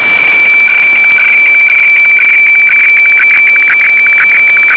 Fax 120 LPM
Fax120.wav